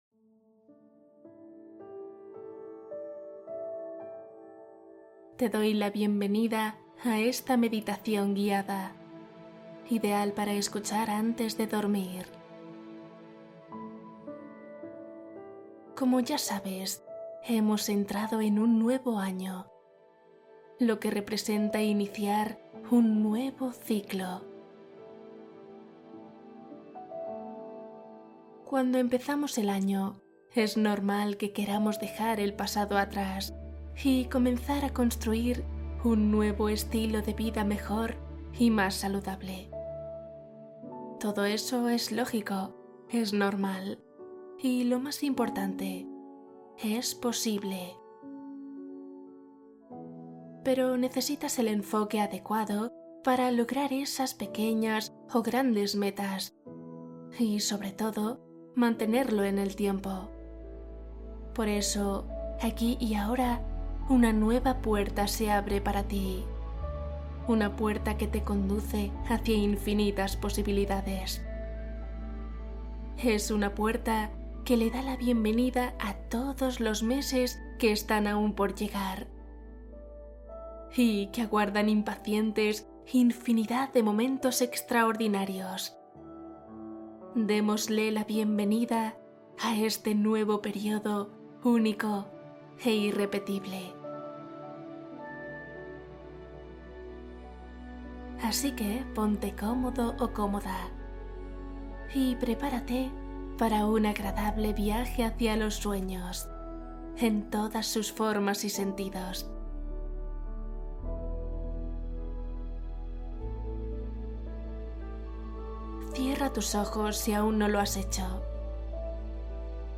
Meditación y cuento | Propósitos, abundancia y plenitud